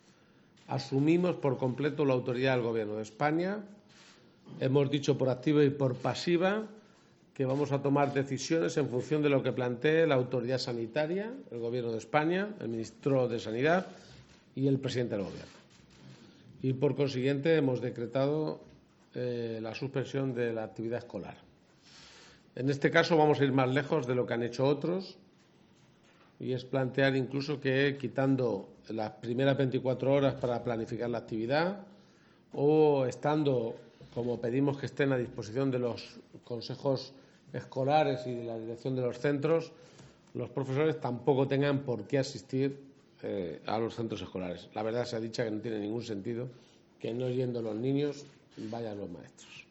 En rueda de prensa, ha pedido que, ya que se cierran los centros escolares, la recomendación pasa por mantener a los alumnos en casa, "limitar los movimientos" y no salir a la calle.